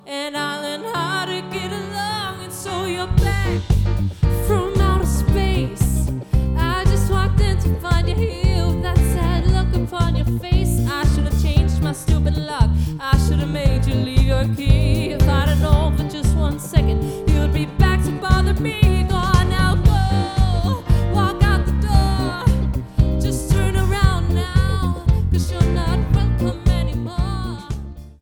Vokal
Guitar
Keys
Trommer
• Coverband